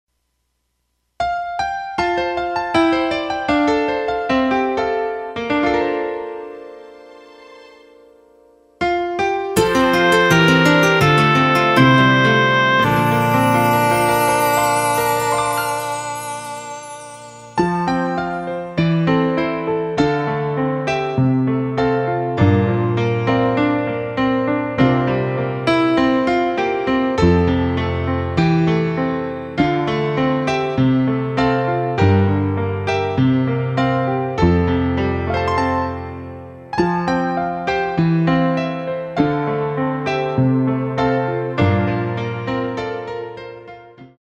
키 F 가수
원곡의 보컬 목소리를 MR에 약하게 넣어서 제작한 MR이며